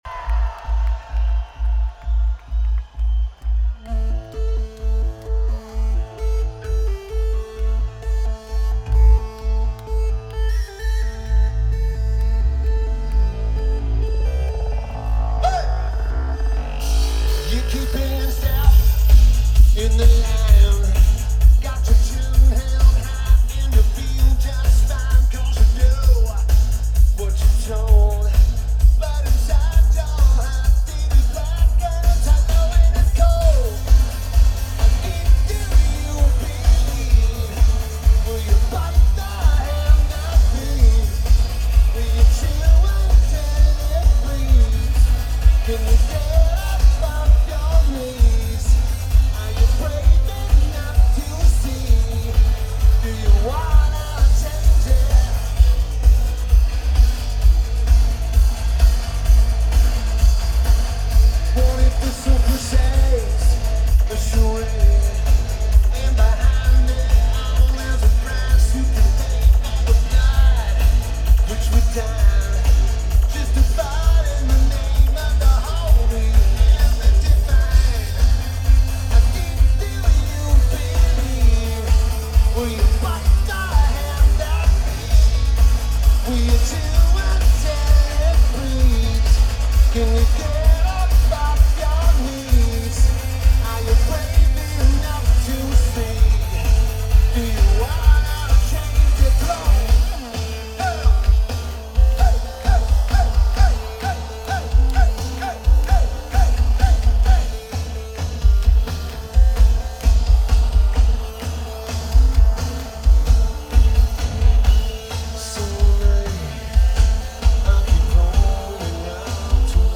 Welcome To Rockville
Lineage: Audio - AUD (Sony PCM-A10)